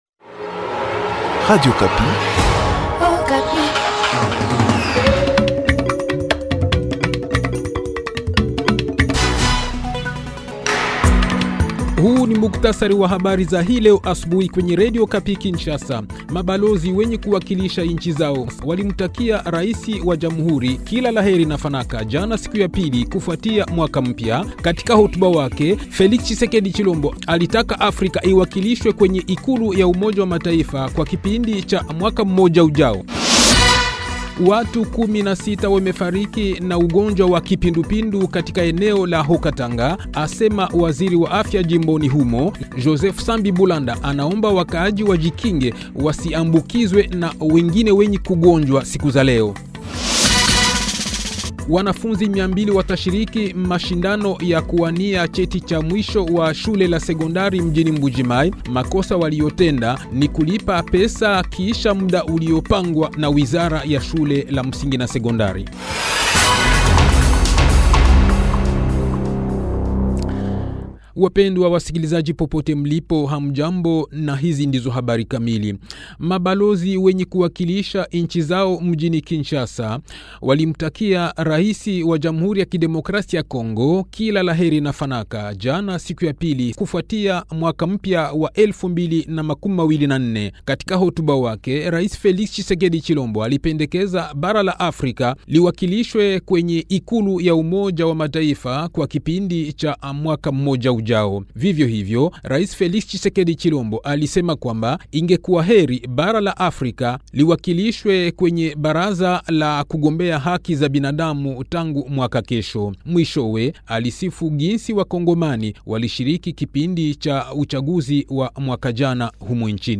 Le journal Swahili de 5 h, 31 janvier 2024